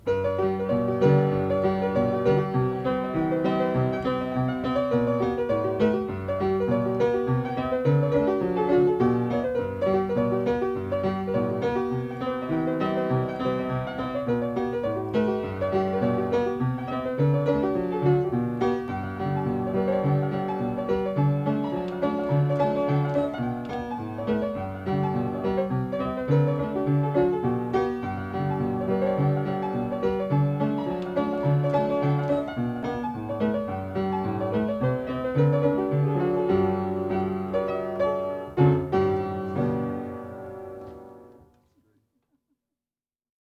Princess Reel (pno solo